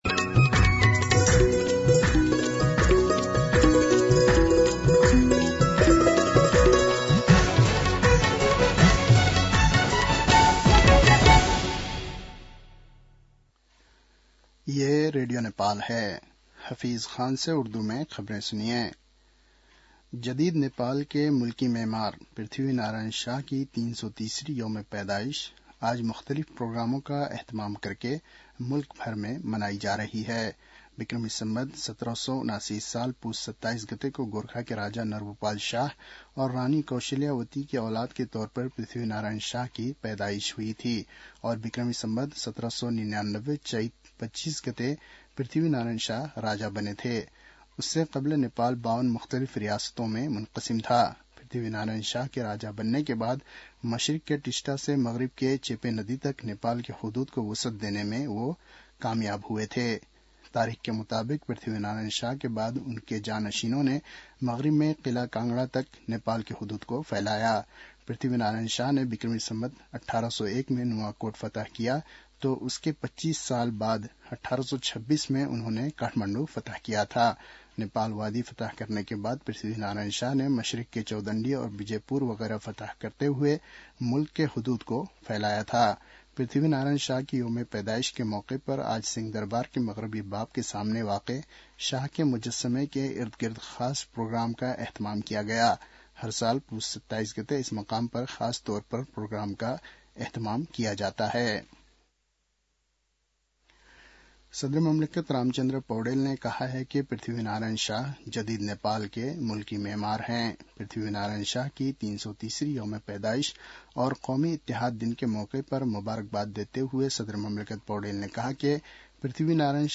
उर्दु भाषामा समाचार : २८ पुष , २०८१
Urdu-News-.mp3